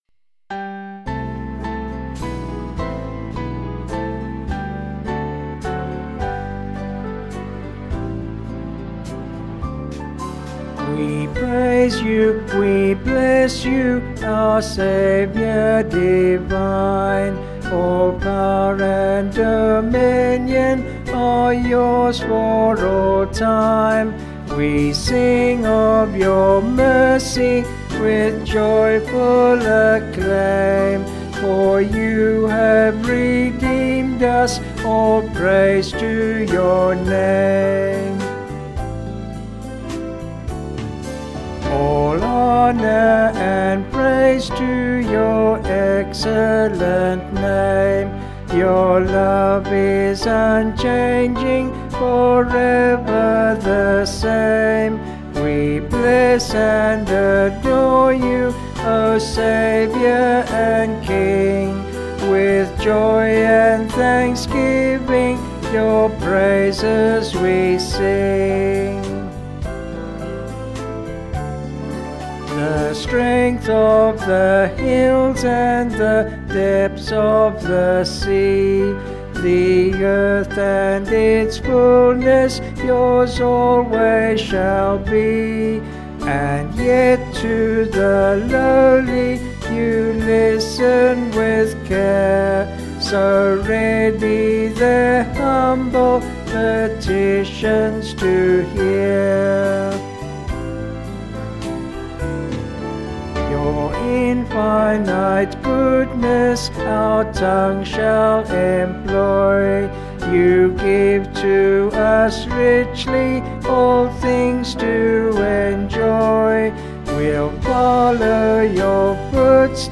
Vocals and Band   263.8kb Sung Lyrics